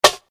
nt snare 15.wav